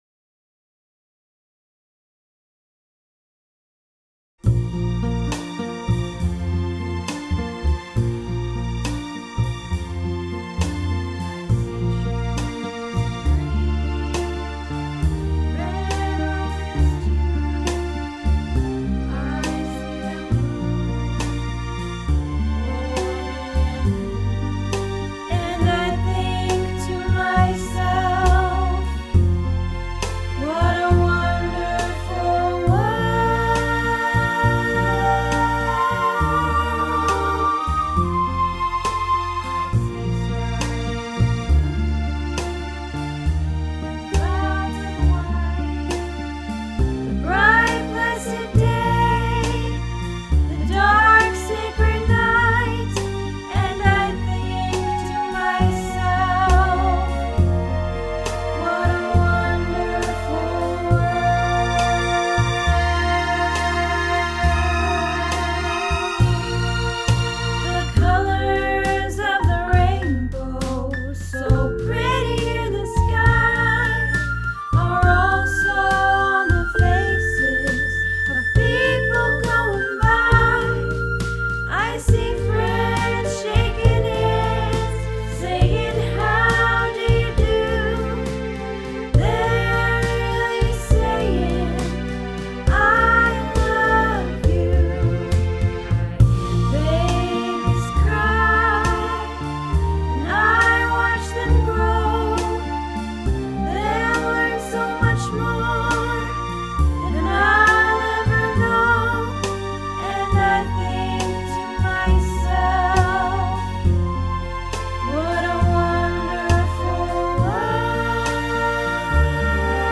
What a Wonderful World - Soprano